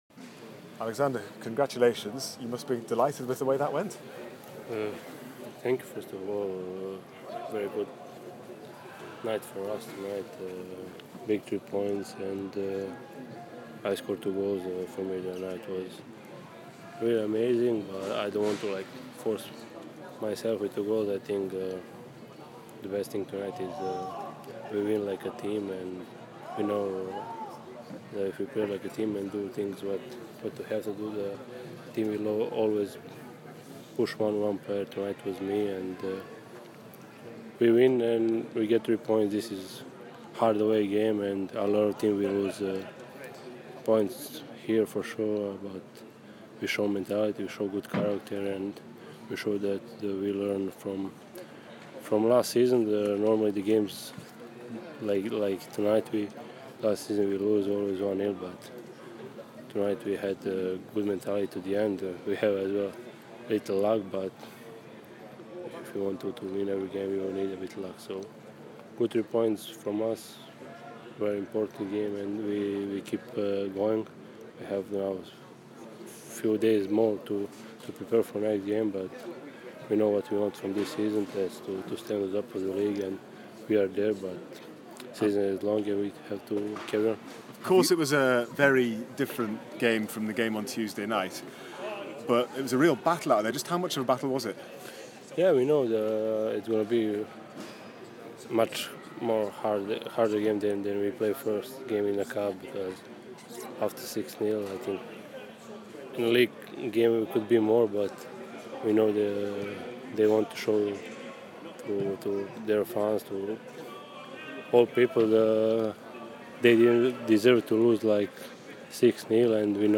Aleksandar Mitrović talks to BBC Newcastle after scoring his second brace against Preston in five days.